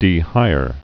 (dē-hīr)